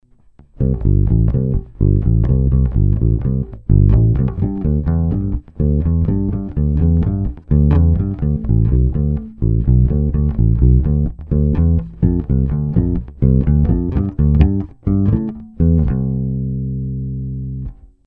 Mizerný nahrávky spíchnutý za pár minut.. Tempo nic moc, přeznívání atd. Není to pořádný A/B porovnání - ani dvě z nich nemaj stejný struny, 3*flat, 1*round..
olše/brazilskej palisandr/krkovej snímač/bez tónovky